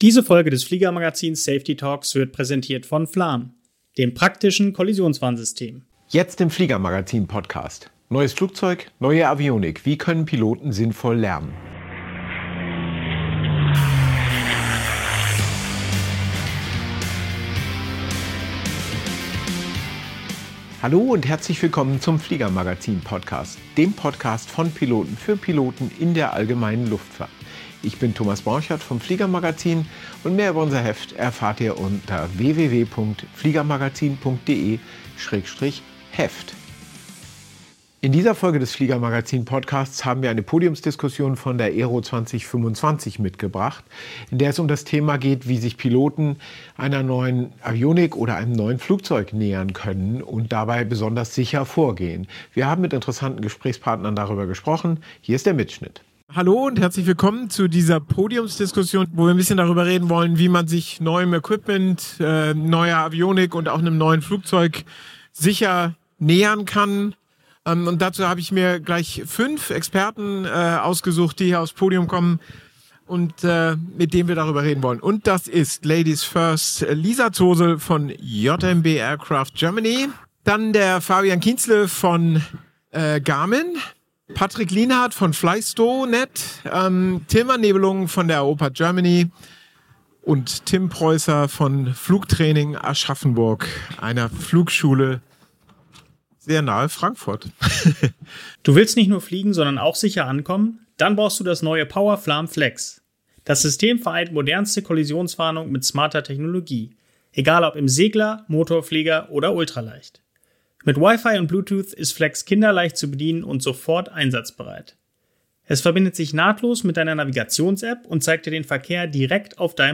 Wie gelingt ein sicherer Umstieg auf ein neues Flugzeugmuster oder moderne Avionik? In einer Podiumsdiskussion auf der AERO 2025 geben fünf erfahrene Experten Tipps, welche Trainings wirklich sinnvoll sind, wo Stolperfallen lauern und wie Piloten individuelle Wege zum sicheren Umgang mit Technik fin...